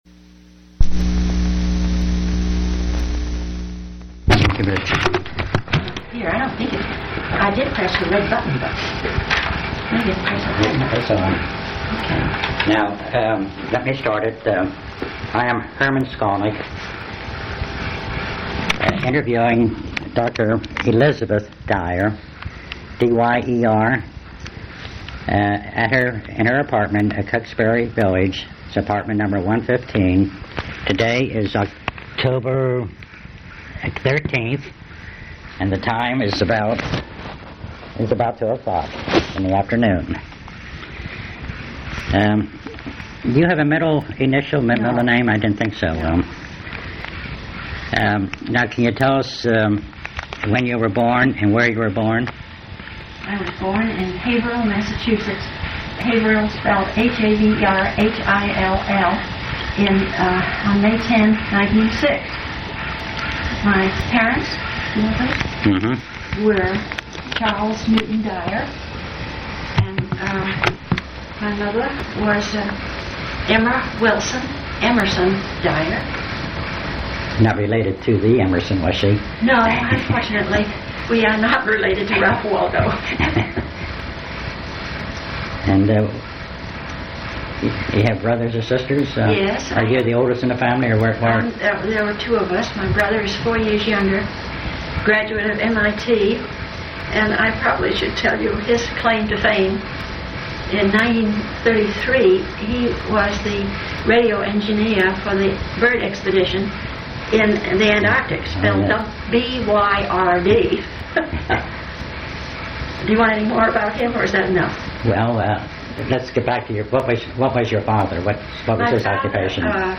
Place of interview Delaware--Hockessin
Genre Oral histories